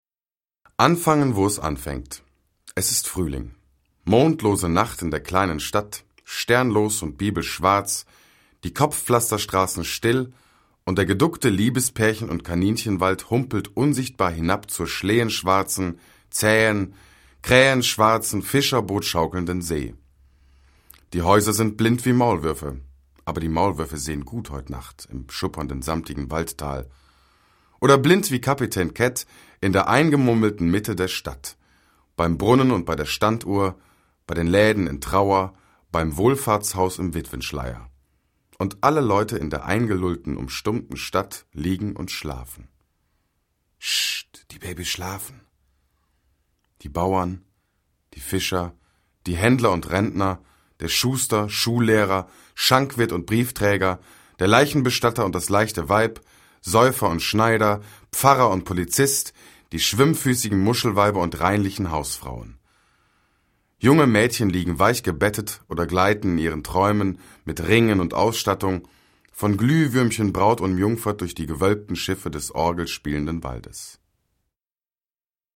deutscher Sprecher
Sprechprobe: eLearning (Muttersprache):
german voice over artist